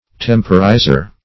Temporizer \Tem"po*ri`zer\, n.